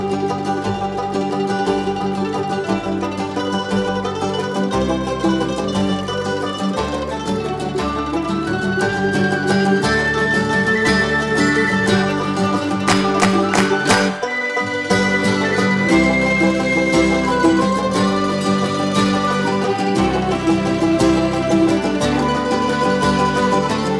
Irish Backing Tracks for St Patrick's Day